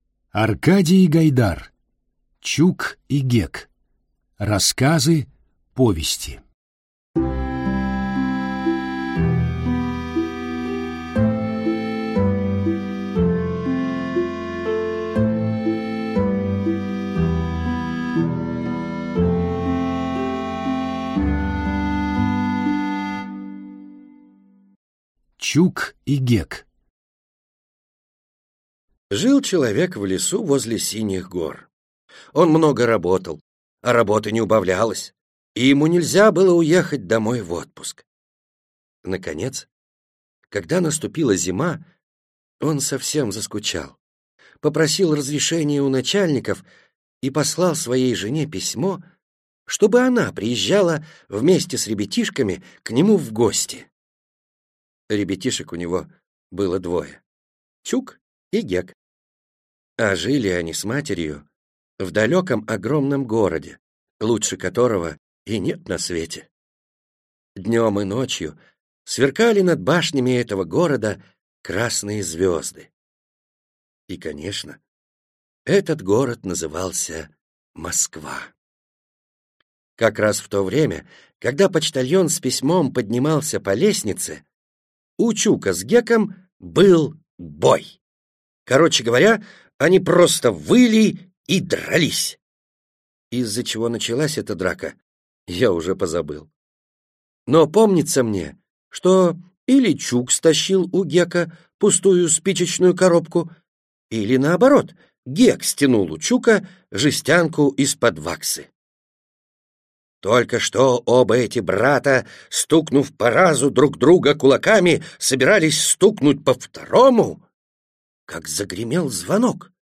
Аудиокнига Чук и Гек. Рассказы. Повести | Библиотека аудиокниг